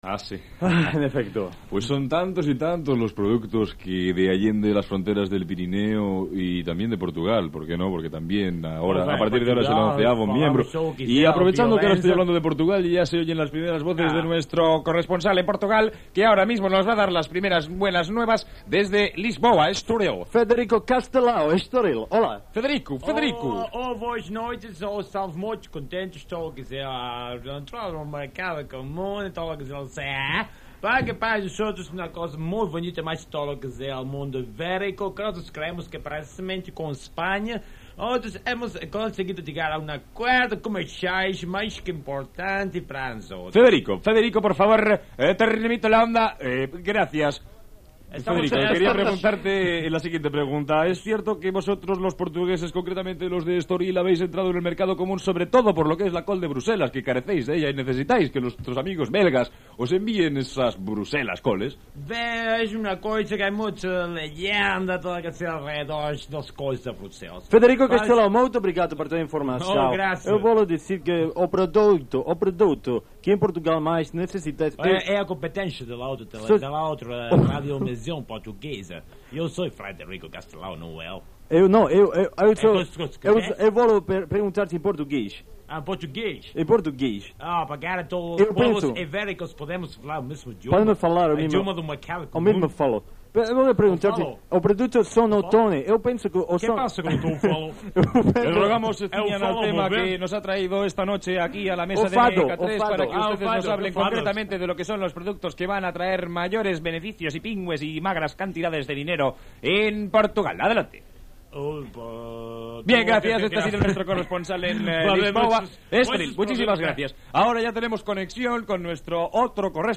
Careta del programa, comentari sobre l'entrada d'Espanya a la Unió Europea, hora, equip del programa, publireportatge "El guantazo", roda de corresponsals sobre el mercat comú i la Unió Europea (Portugal, Regne Unit, Alemanya…)
Entreteniment
FM